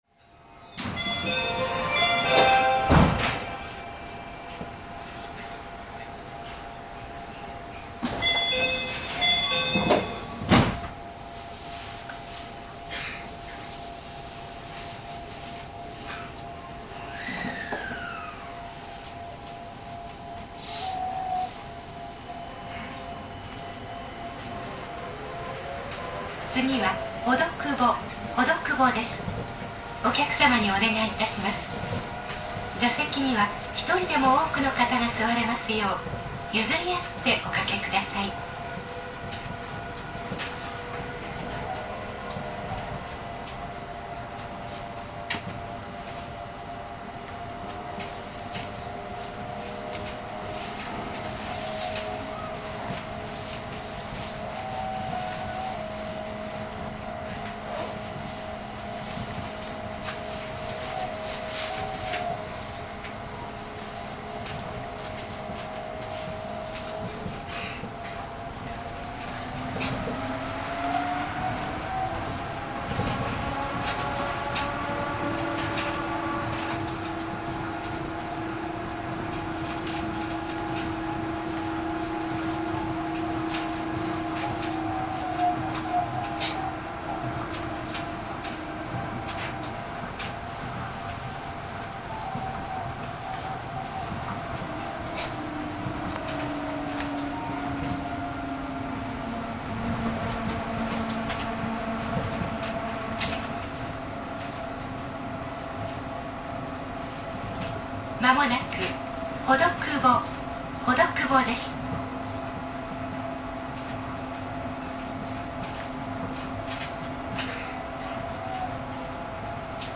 走行音
TM04 1000系 多摩動物公園→程久保 2:30 9/10 上の続きです